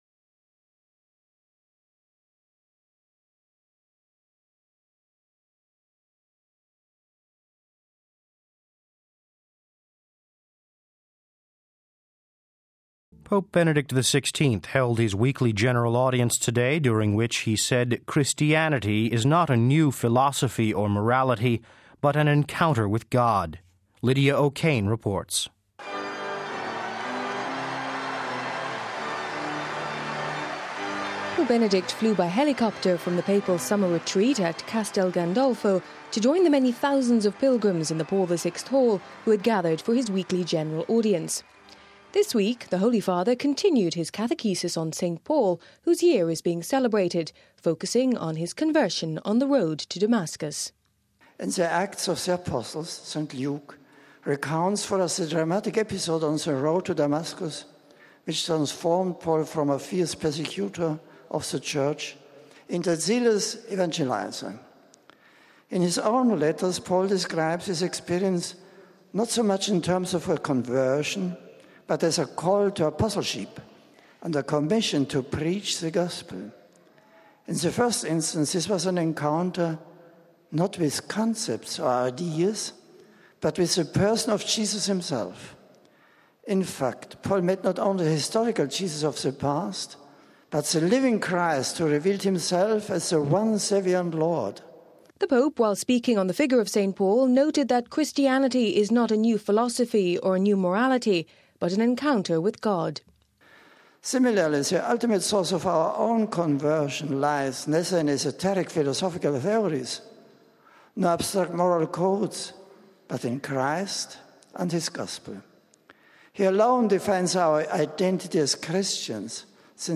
Pope Benedict flew by helicopter from the Papal summer retreat at Castelgandolfo to join the many thousands of pilgrims in the Paul the VI hall who had gathered for his weekly general audience.